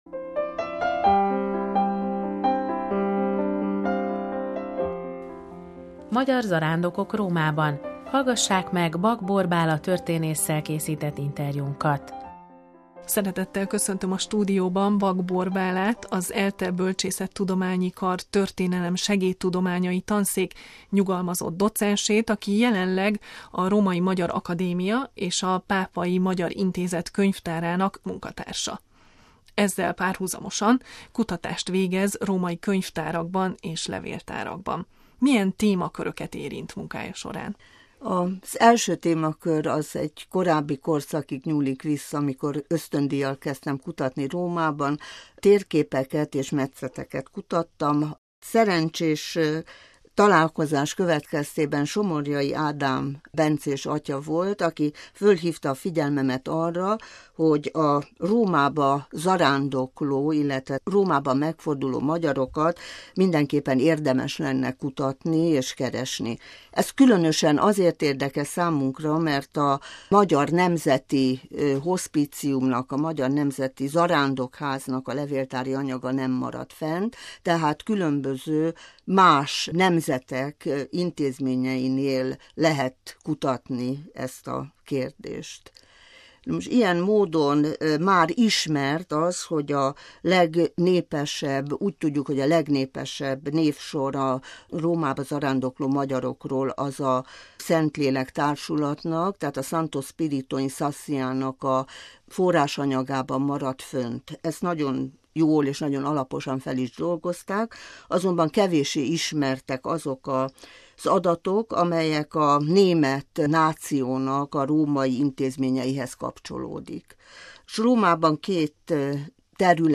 Magyar zarándokok a XV. és XVI. századi Rómában – interjú